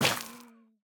Minecraft Version Minecraft Version 1.21.5 Latest Release | Latest Snapshot 1.21.5 / assets / minecraft / sounds / block / soul_soil / break2.ogg Compare With Compare With Latest Release | Latest Snapshot